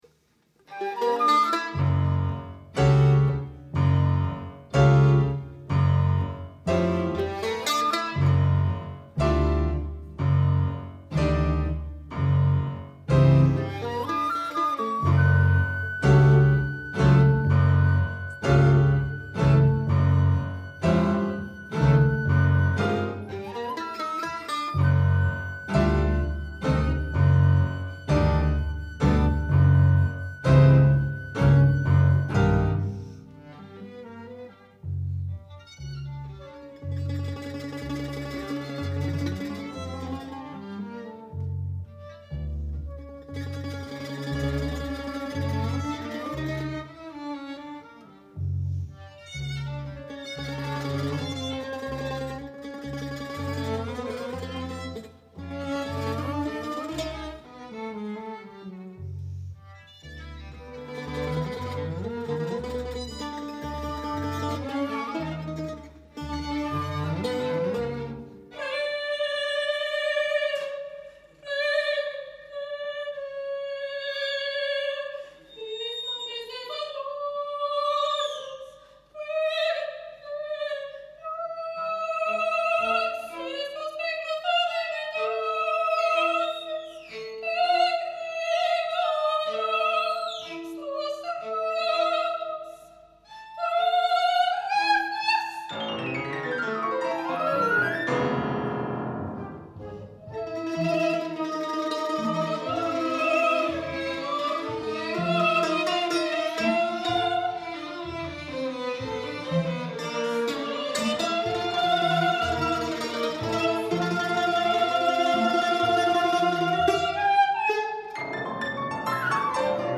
Lookin to the east for flute and piano
Live performance